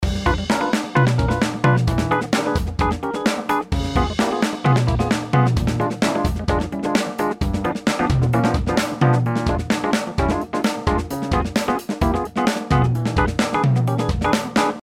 Wir kommen zu den Electric Pianos – Rhodes, Wurlitzer und andere: